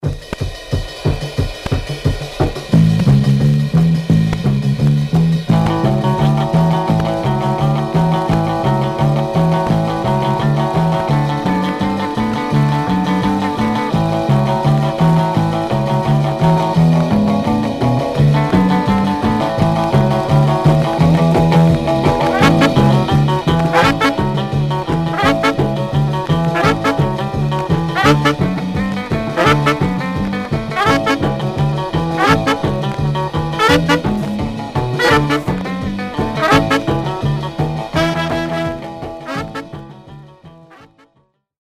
Surface noise/wear
Mono
R & R Instrumental Condition